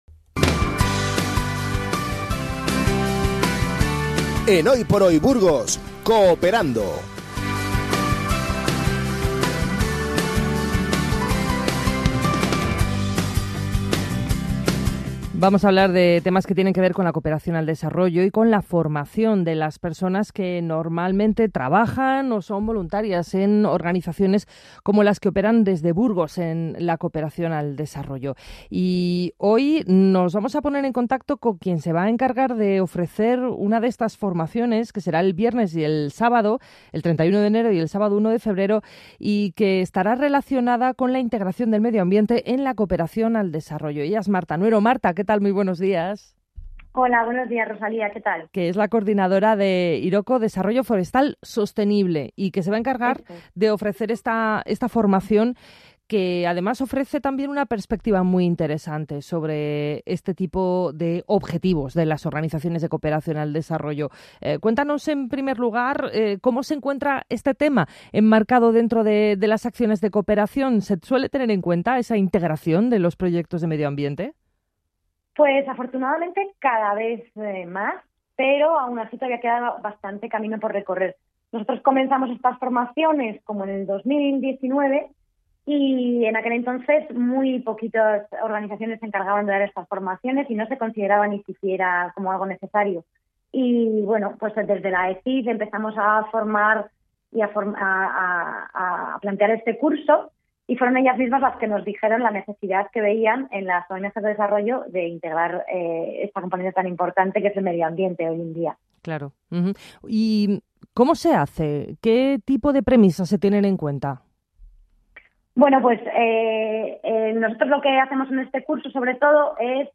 Audio Ser Burgos - Curso de Integración del medioambiente en la cooperación para el desarrollo en Burgos